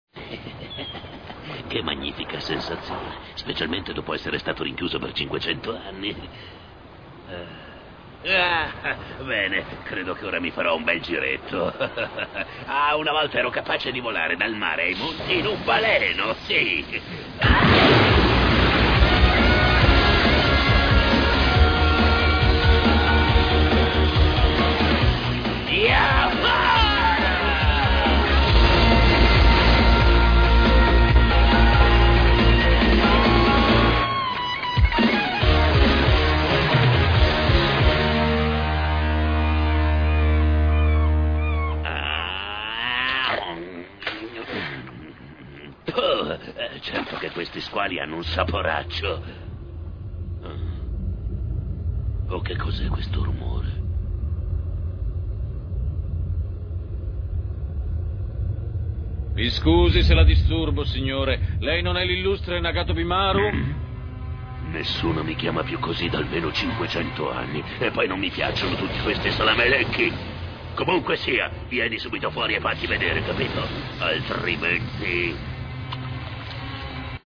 dal cartone animato "Ushio e Tora", in cui doppia Tora.